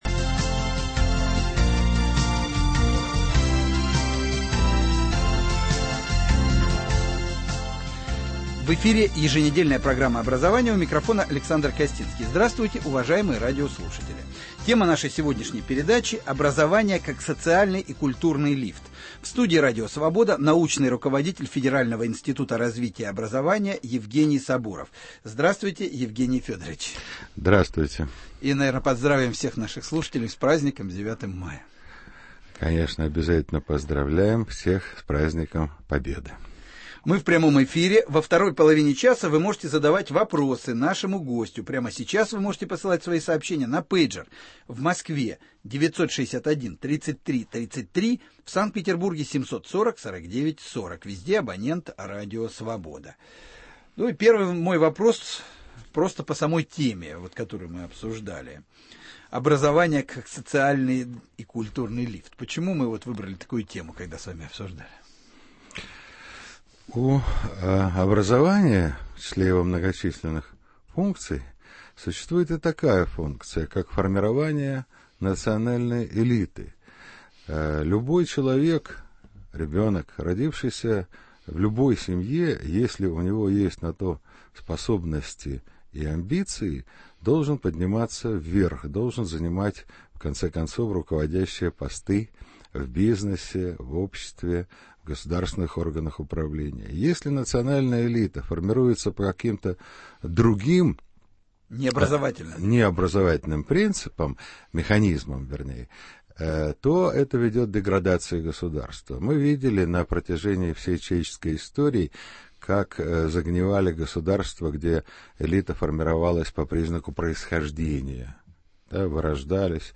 Образование, как социальный и культурный лифт . В студии радио "Свобода": научный руководитель федерального Института развития образования Евгений Сабуров